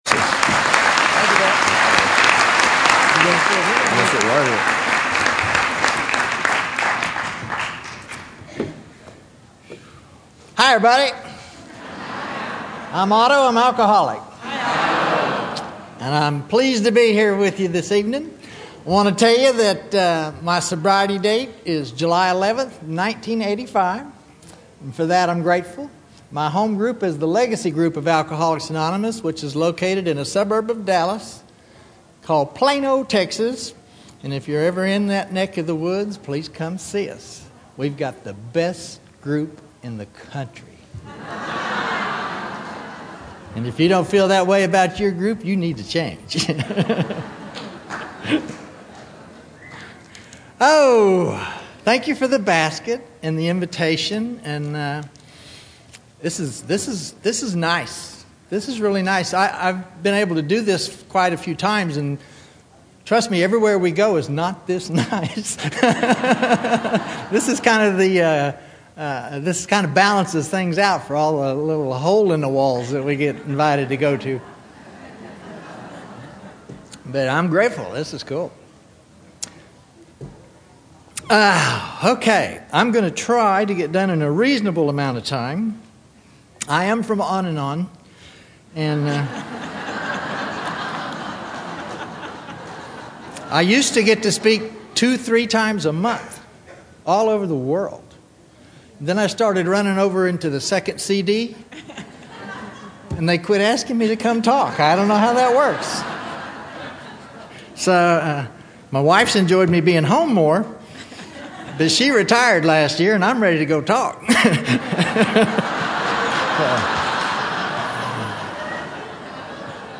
San Diego Spring Roundup 2009